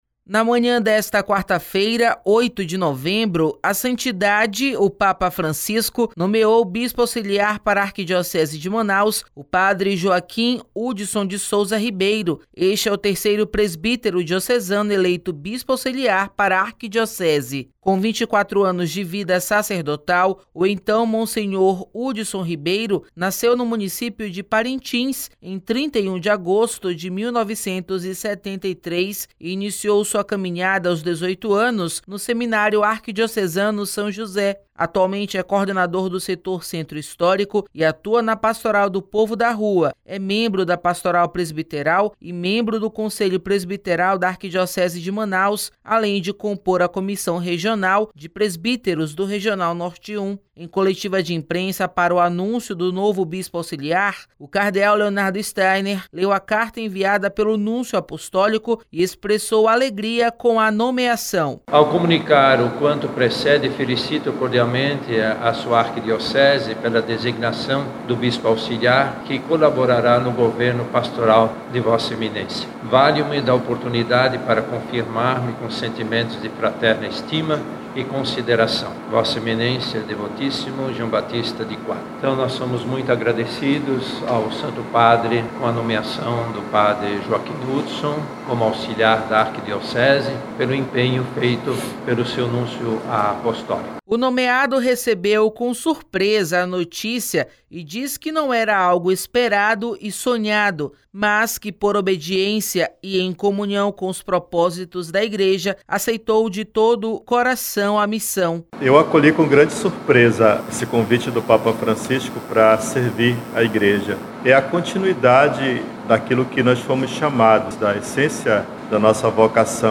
Pe. Hudson Ribeiro concede coletiva sobre sua nomeação para bispo auxiliar de Manaus
Em coletiva de imprensa para o anúncio do novo bispo auxiliar, o Cardeal Leonardo Steiner leu a carta enviada pelo Núncio Apostólico e expressou alegria com a nomeação.